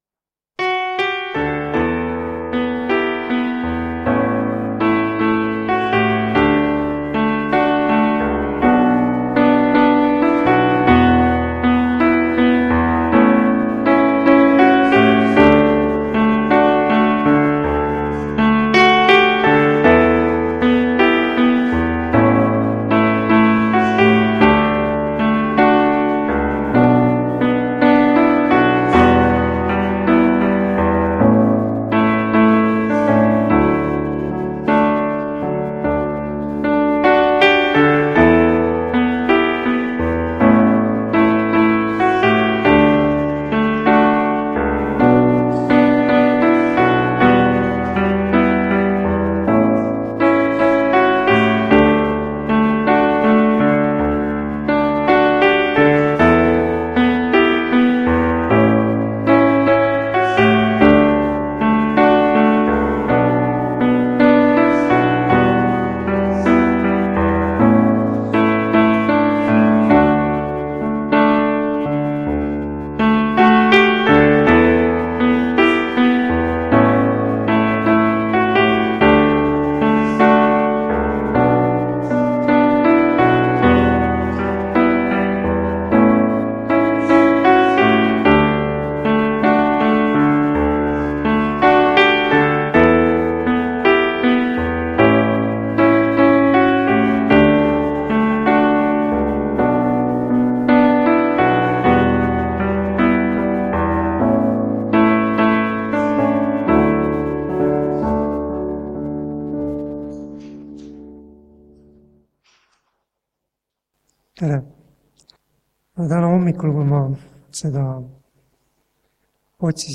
Tunnistuste koosolek (Haapsalus)